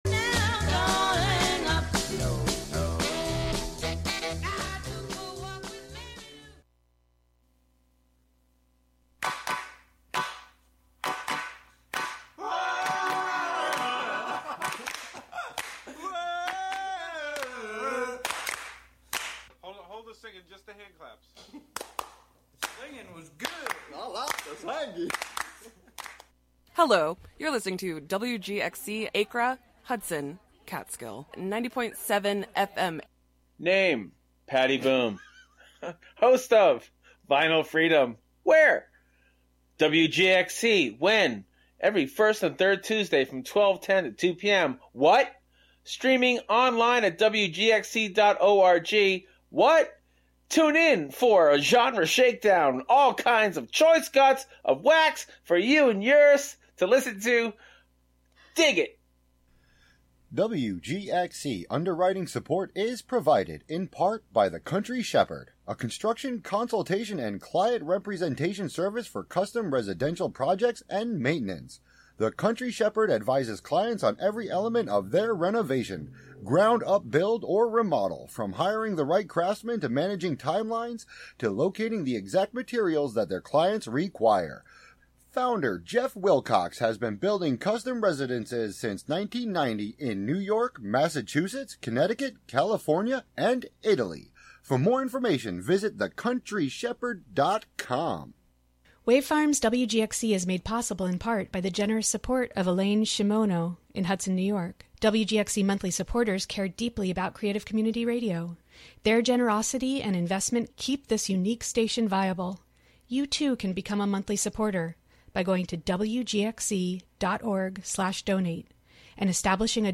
"Long Pause" is an invitation to slow down into interstitial realms through sound. With a blend of song, field recording, archival audio, and conversation, the show explores the thin spaces between the ordinary and sacred, human and nonhuman, particular and universal, and visible and invisible, through a different sonic theme and/or medium each month.